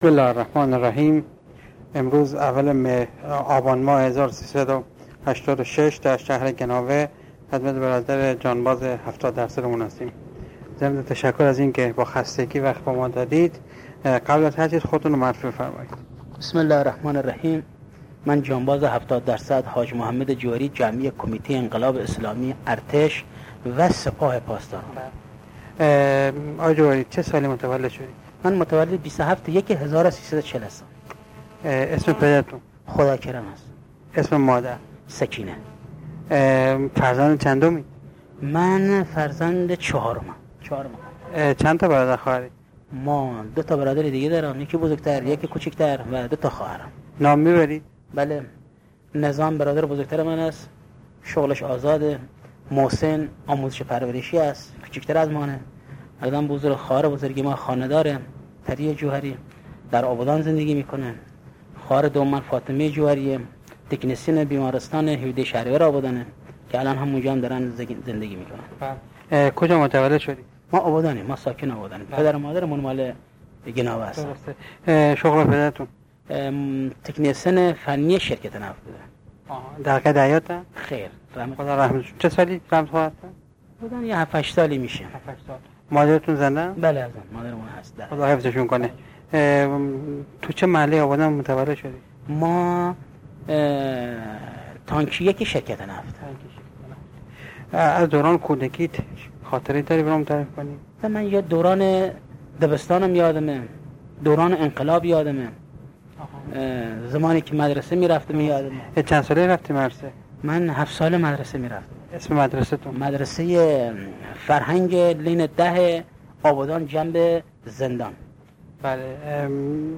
خیلی صمیمی و با هیجان خاصی به سؤالاتمان پاسخ داد. امید به زندگی و کار و تلاش در این جانباز موج میزند.
مصاحبه صوتی